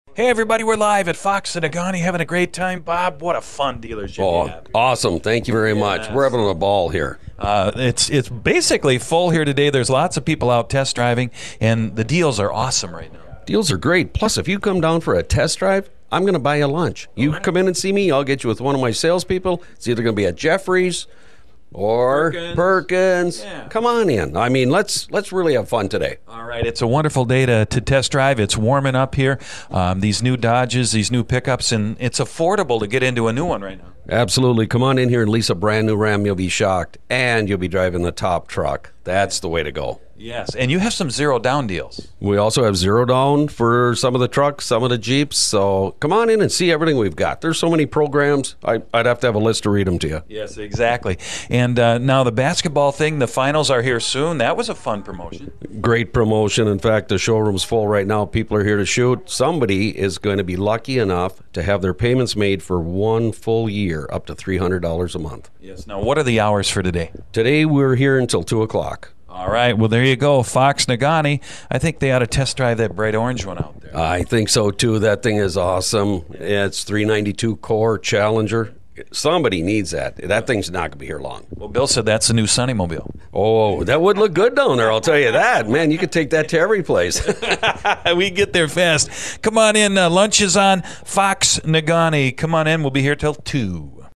to bring all the action and deals to you live, on Sunny 101.9 FM and 103.3 WFXD!